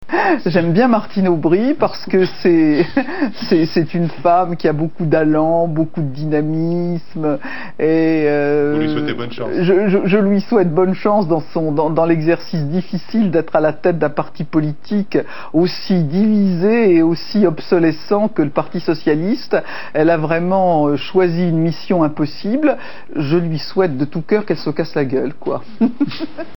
Bachelot sur public sénat (TV) le 27 janvier 2009 !